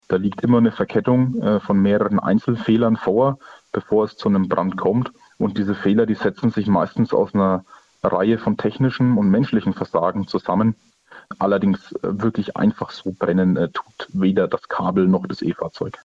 Interview: Was tun, wenn das Ladekabel vom E-Auto brennt?